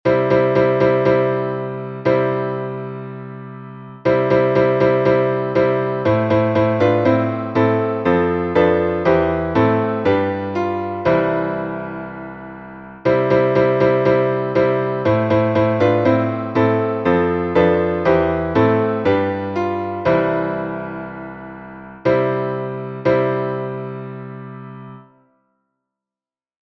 карловацкий напев